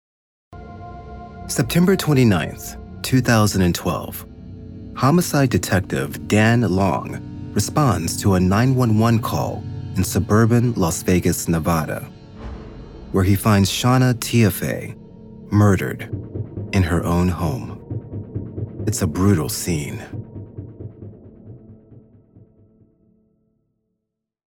Male
Adult (30-50), Older Sound (50+)
Narration
All our voice actors have professional broadcast quality recording studios.